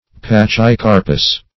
Search Result for " pachycarpous" : The Collaborative International Dictionary of English v.0.48: Pachycarpous \Pach`y*car"pous\, a. [Pachy- + Gr. karpo`s fruit.]
pachycarpous.mp3